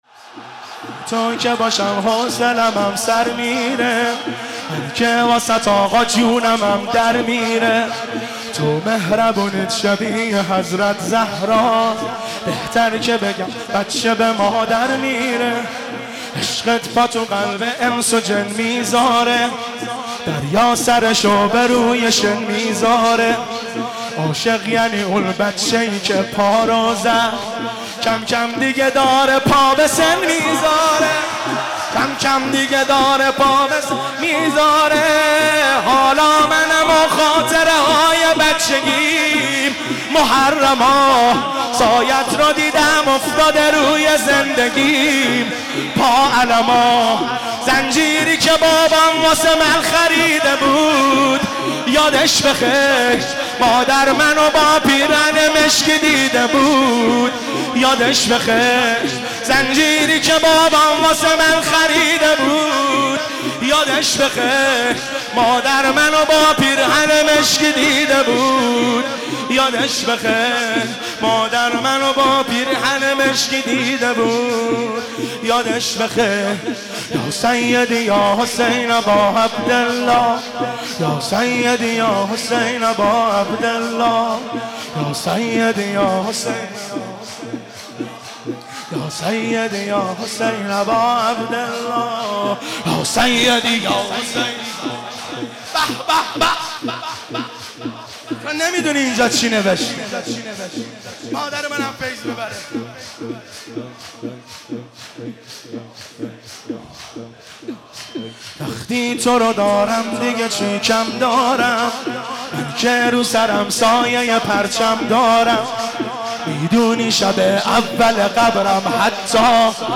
بی تو که باشم حوصلمم سرمیره(شور زیبا